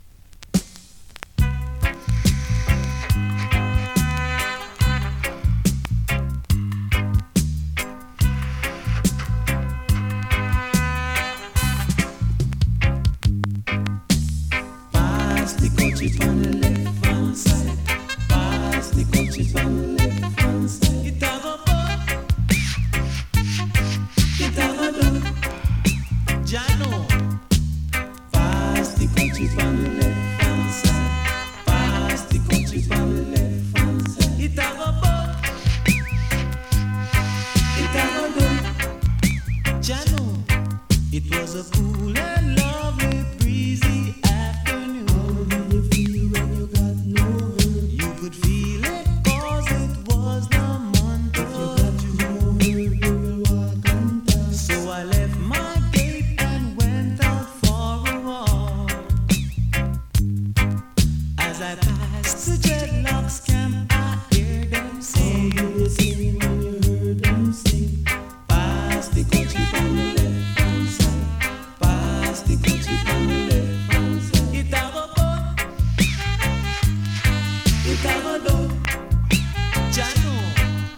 2020 NEW IN!!SKA〜REGGAE!!
スリキズ、ノイズかなり少なめの